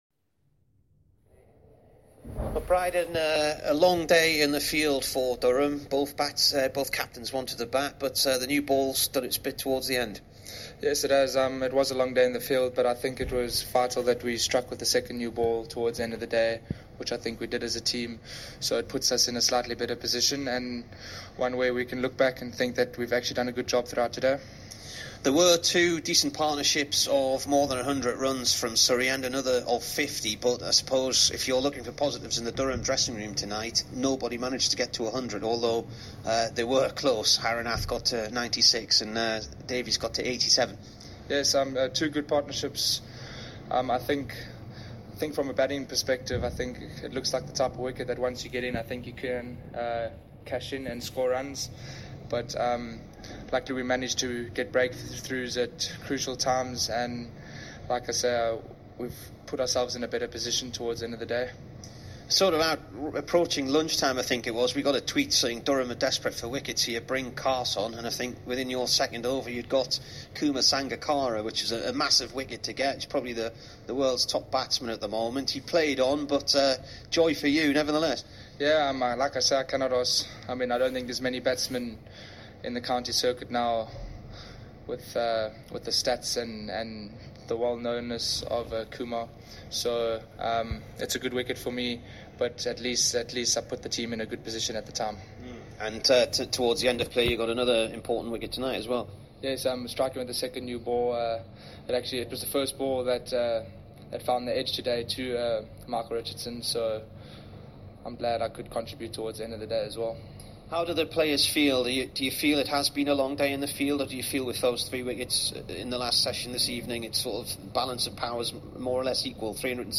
Here is the Durham bowler after 2 wickets on day one at Surrey.